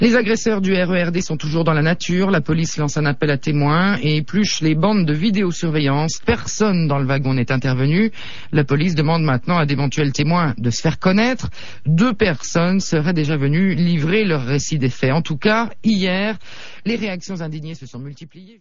[1Certains des extraits sonores qui suivent résultent d’un montage.